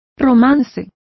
Complete with pronunciation of the translation of ballads.